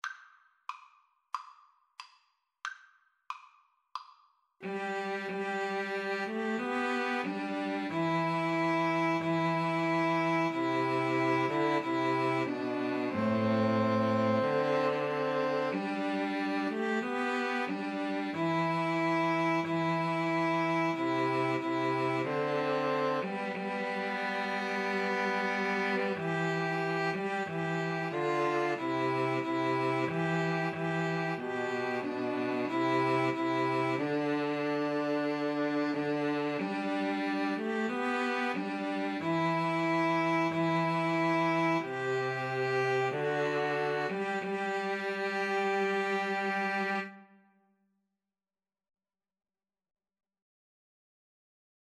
Andante = c. 92
2-Violins-Cello  (View more Easy 2-Violins-Cello Music)
Classical (View more Classical 2-Violins-Cello Music)